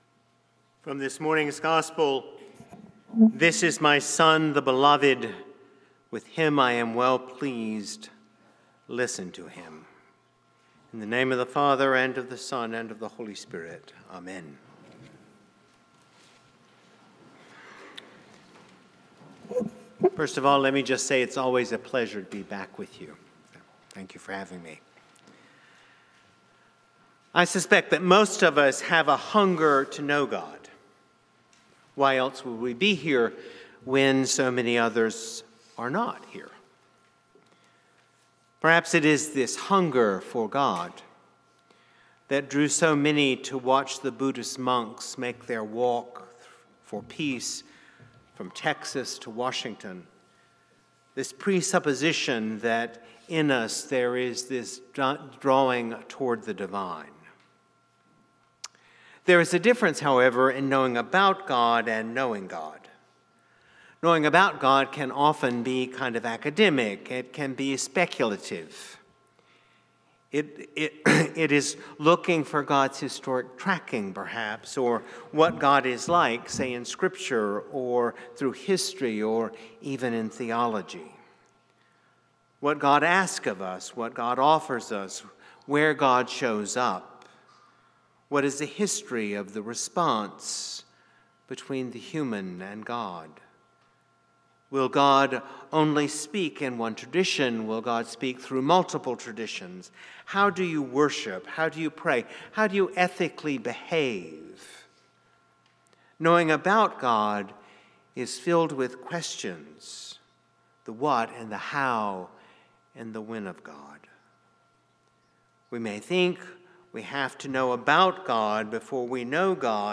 St-Pauls-HEII-9a-Homily-15FEB26.mp3